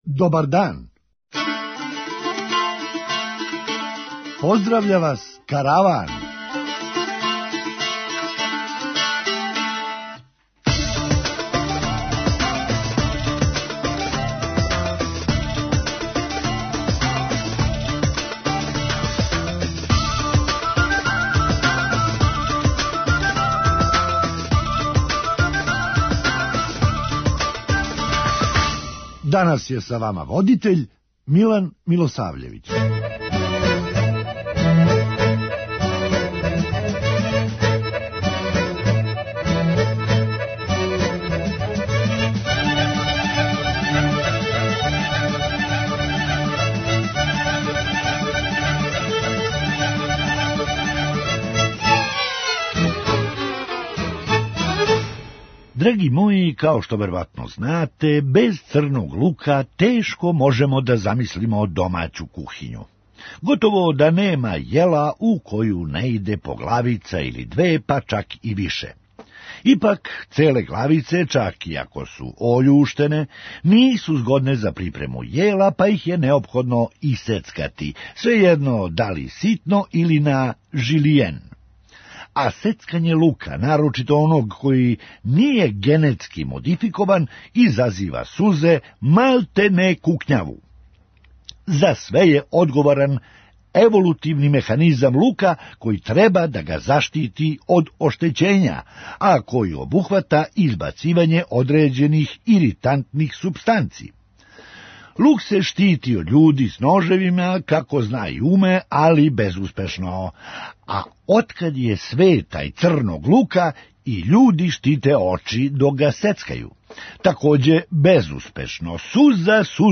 Хумористичка емисија
А кад не би било лопте, изгледала би као такмичење у боди-билдингу. преузми : 8.95 MB Караван Autor: Забавна редакција Радио Бeограда 1 Караван се креће ка својој дестинацији већ више од 50 година, увек добро натоварен актуелним хумором и изворним народним песмама.